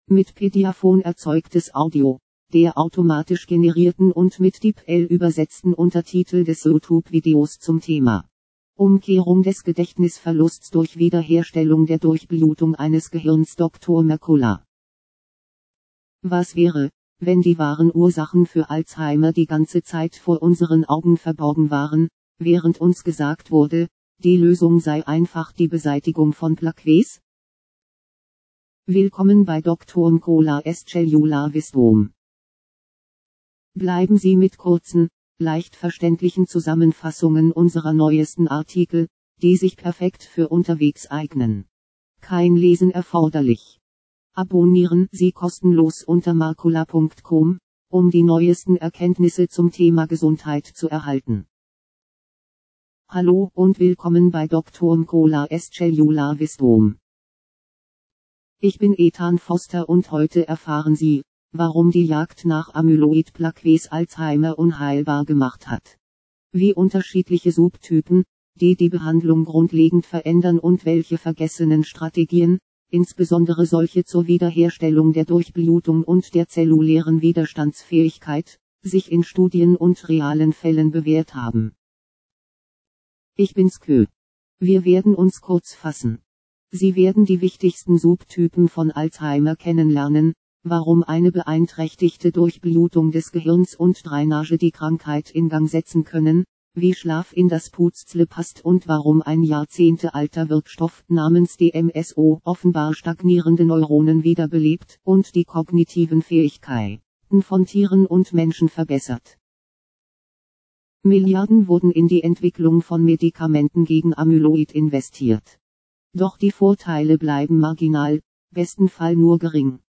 ..vorlesen | Download / Popupfenster �ffnen mp3 | JWD | erzeugt mit Pediaphone Audiotext zum mitlesen PDF ..hier | zum Video